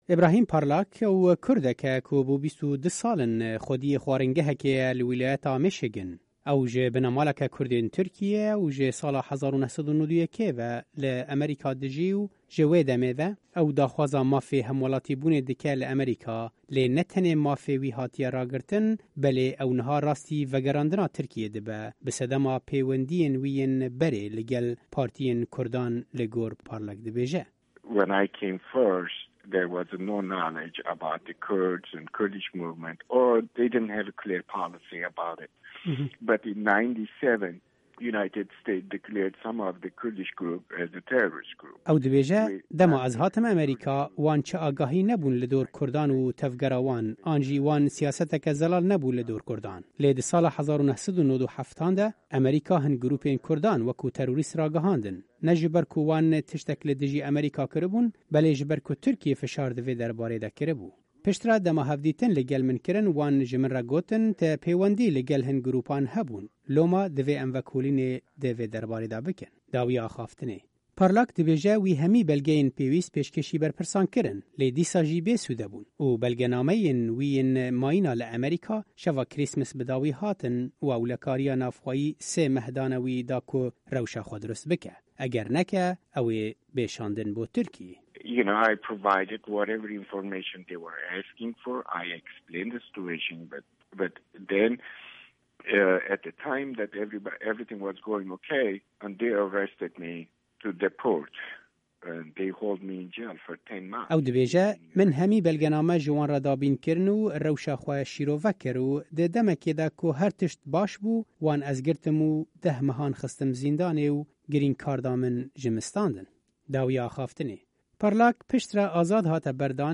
Raport bi Deng SW 01_19_16